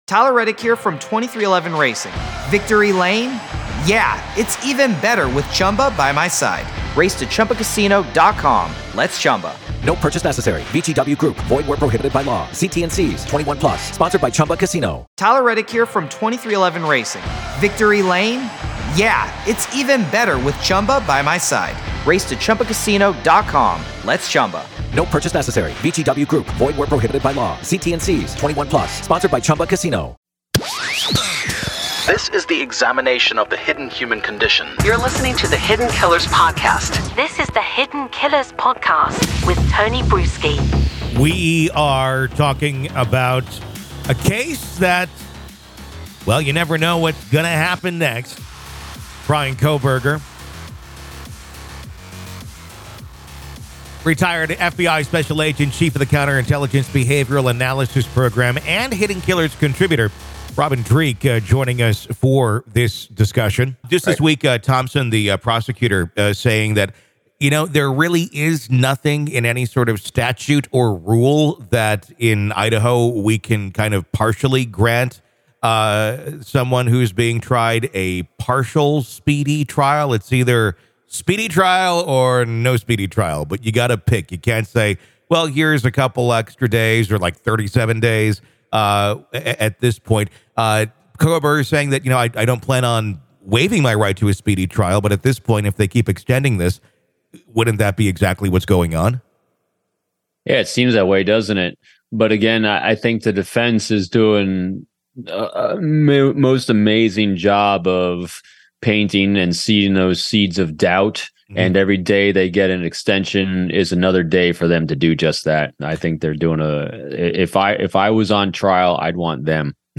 Join us as we delve into the captivating world of true crime with exclusive interviews and unparalleled insights from seasoned FBI agents.